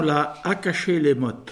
Localisation Sainte-Christine
Catégorie Locution